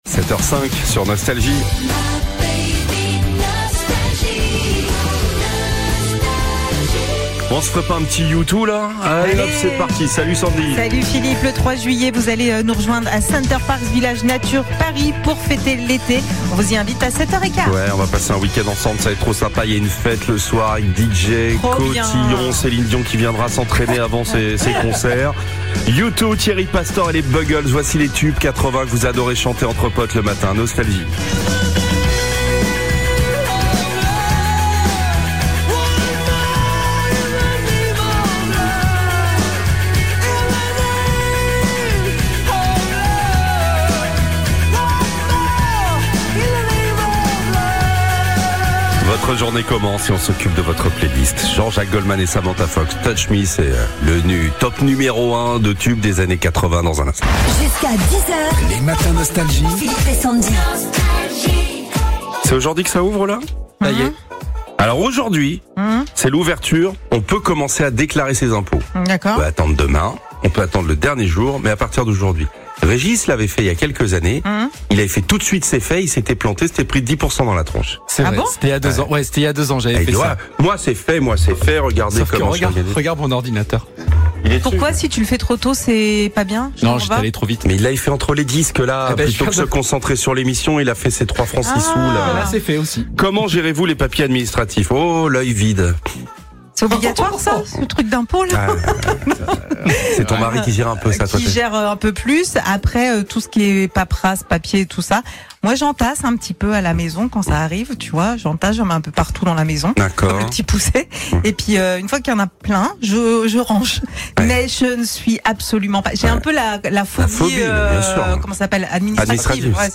Au programme : Bonne humeur et tous les tubes 80 !